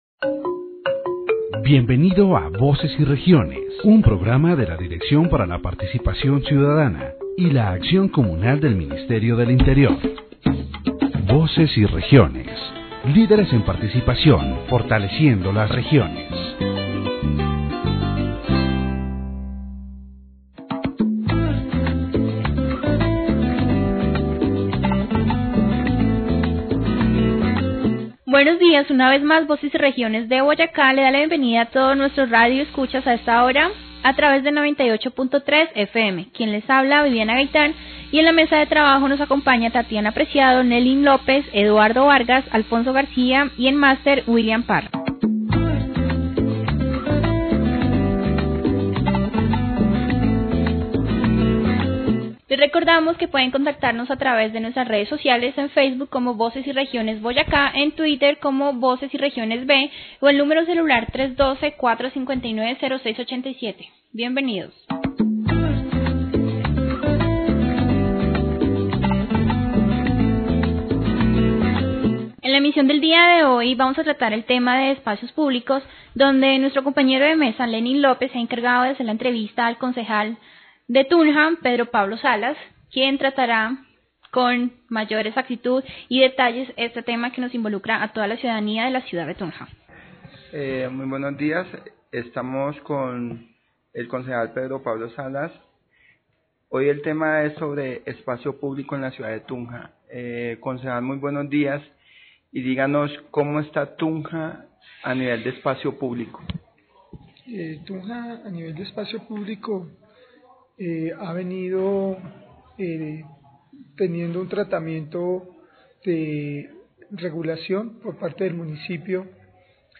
The radio program "Voices and Regions" focused on the problems of public spaces in the city of Tunja.
The participants, including Councilor Pedro Pablo Salas, analyzed the causes of this problem, such as the lack of employment alternatives for street vendors, the lack of investment in infrastructure and the lack of citizen awareness. Solutions were also proposed, such as the creation of popular markets, the promotion of civic culture and the active participation of the community in decision-making.